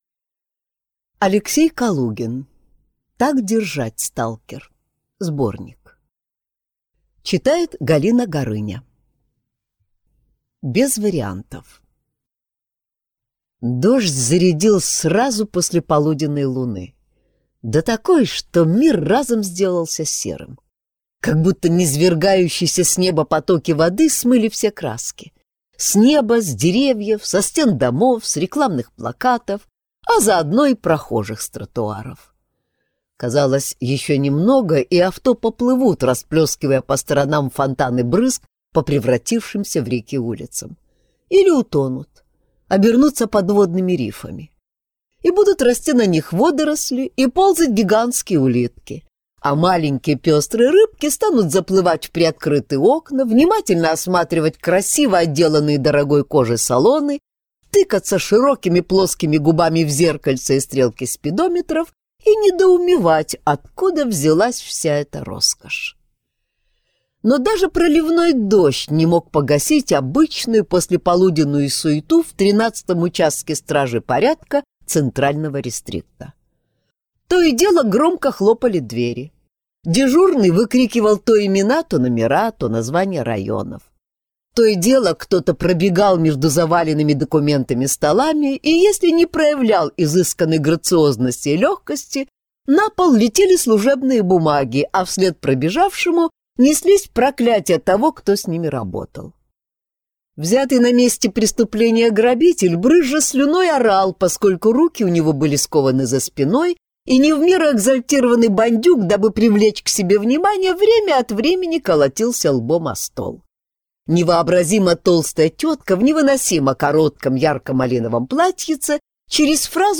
Аудиокнига Так держать, сталкер! (сборник) | Библиотека аудиокниг